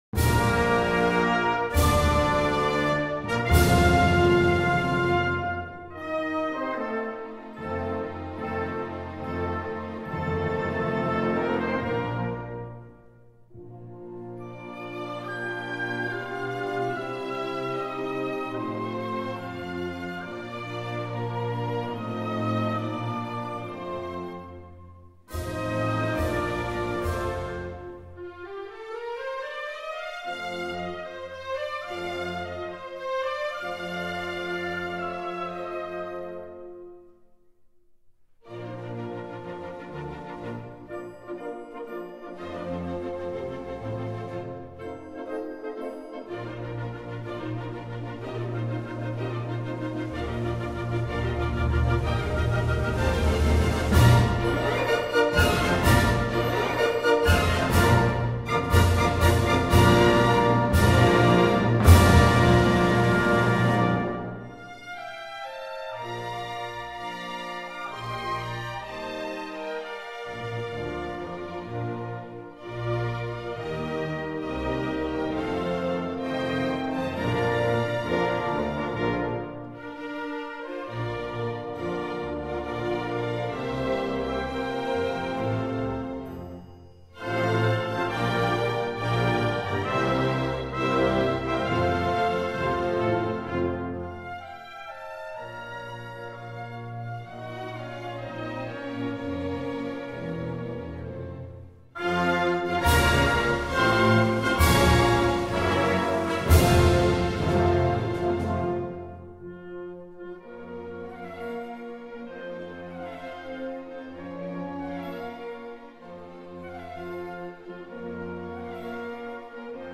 Оркестровая аранжировка